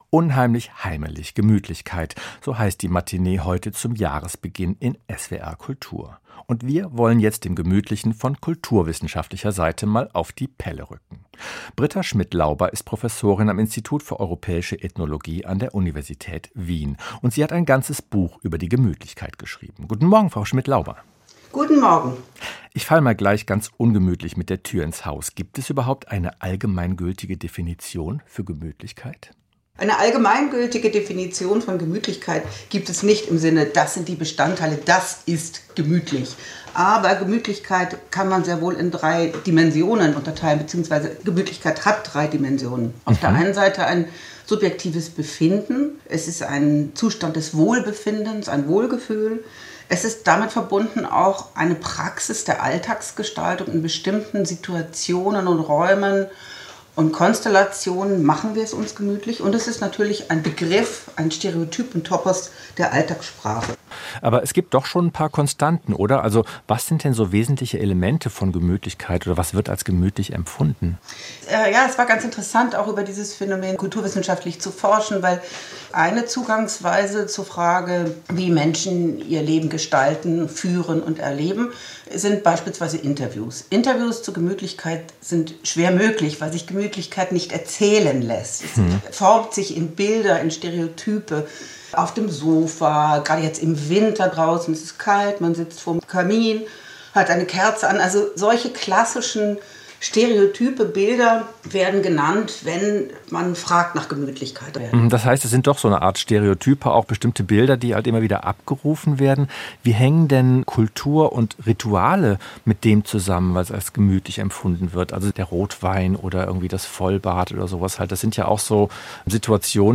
Interview